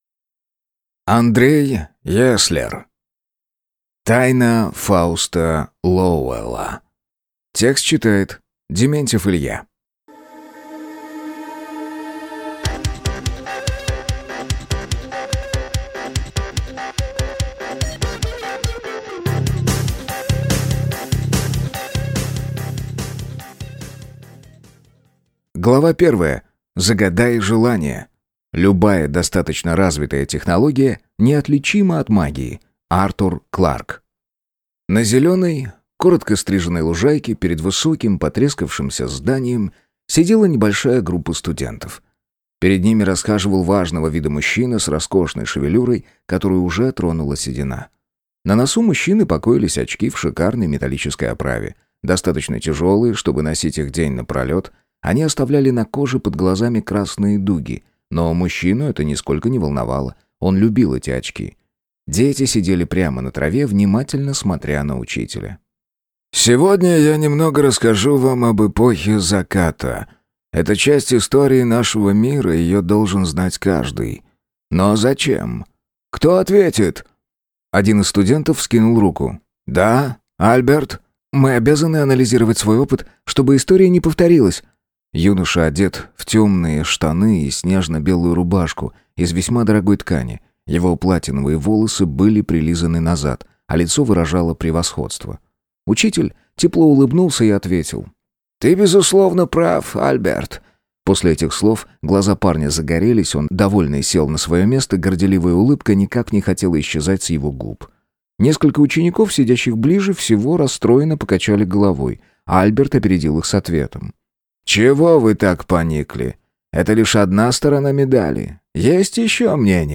Аудиокнига Тайна Фауста Лоуэлла | Библиотека аудиокниг